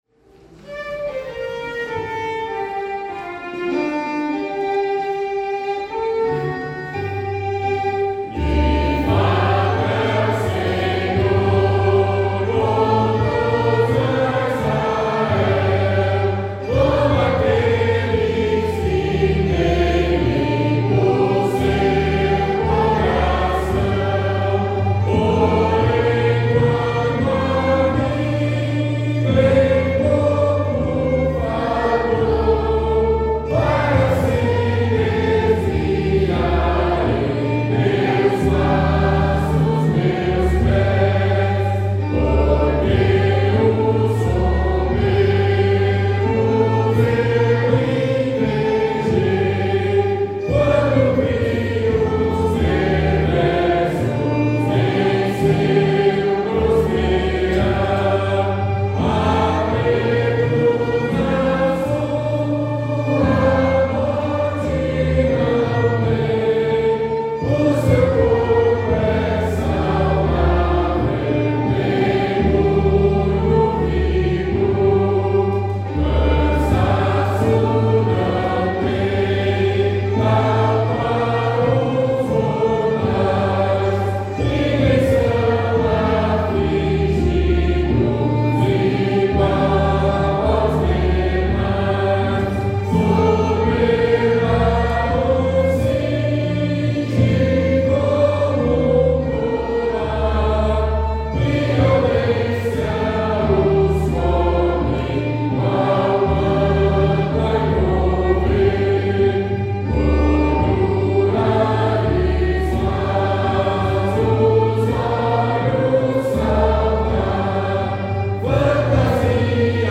salmo_73B_cantado.mp3